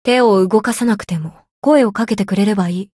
灵魂潮汐-南宫凛-互动-不耐烦的反馈1.ogg